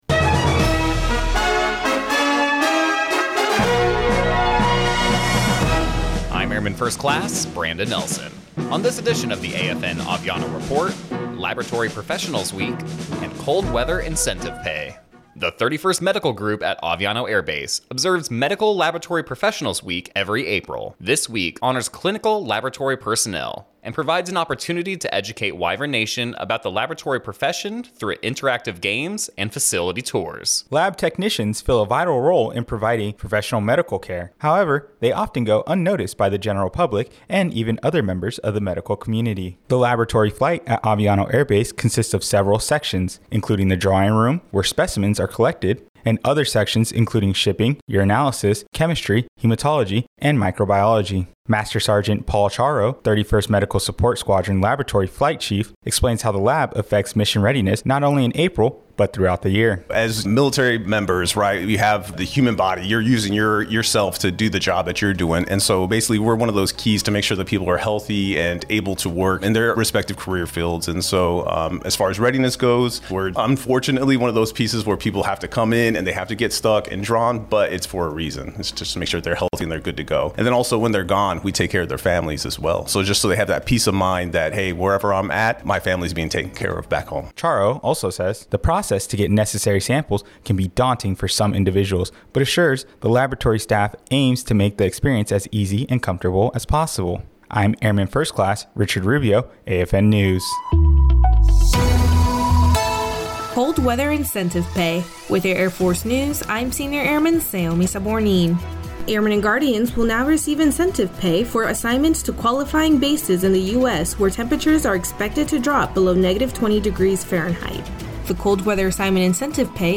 American Forces Network (AFN) Aviano radio news reports on Laboratory Professional’s Week and how laboratory personal contribute to medical care and the 31st Fighter Wing mission.